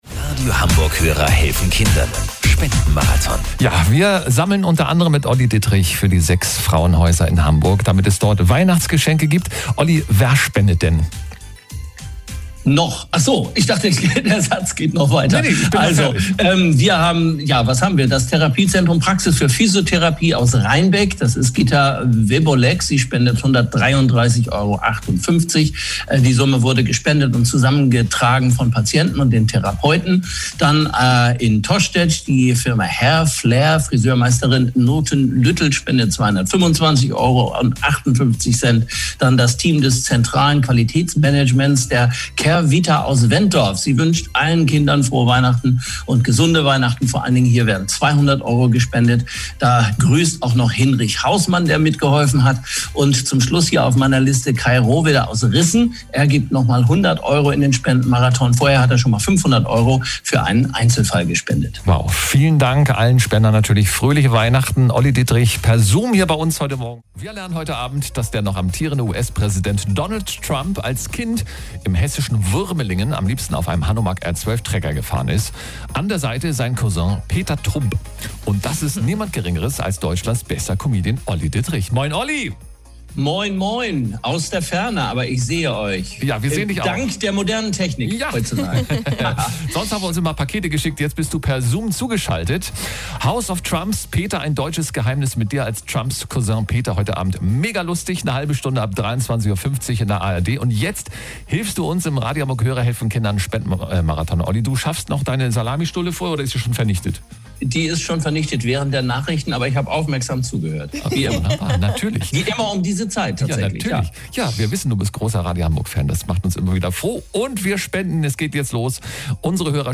Dieses Jahr digitale Besuche im Funkhaus
Stattdessen unterstützen die Promis direkt über das Telefon oder sogar noch etwas persönlicher über eine Zoom-Video-Konferenz die Radio Hamburg Moderatoren im Spendenmarathon.